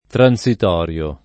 [ tran S it 0 r L o ]